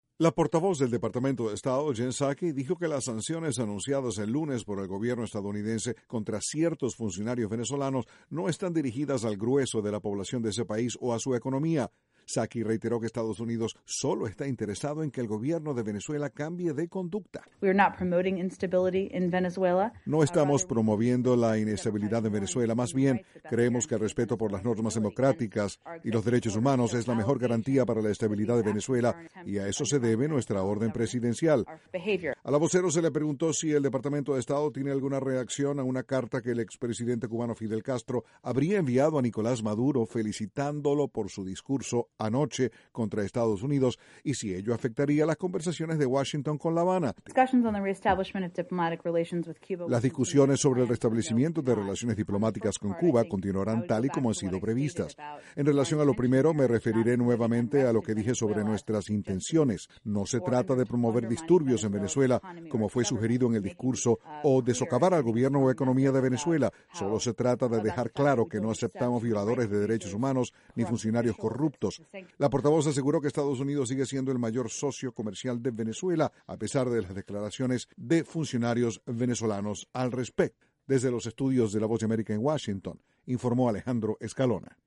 El Departamento de Estado aseguró que las intenciones de Washington no son promover la inestabilidad en Venezuela. Desde la Voz de América informa